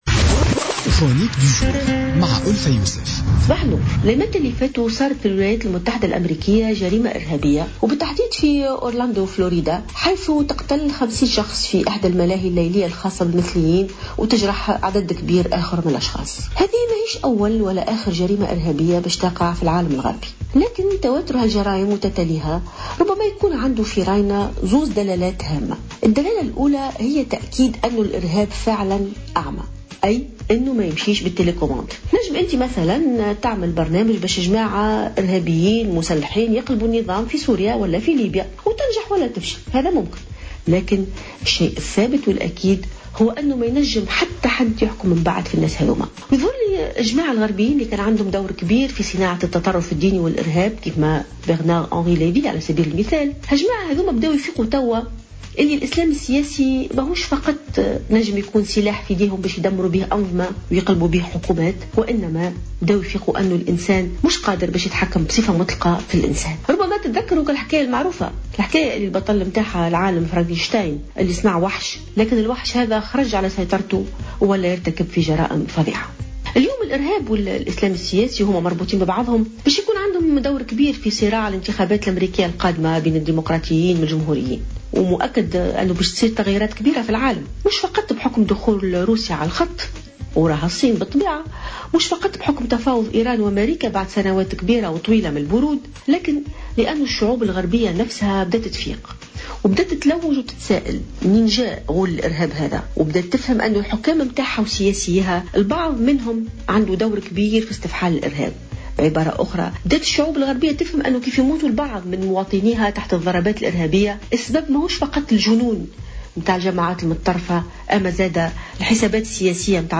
علّقت الجامعية ألفة يوسف في افتتاحية اليوم الأربعاء على تواتر العمليات الإرهابية في دول الغرب، مشيرة إلى أن الإرهاب أعمى ويضرب جميع الدول دون استثناء.